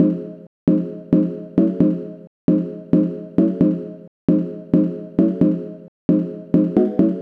Lead 133-BPM F.wav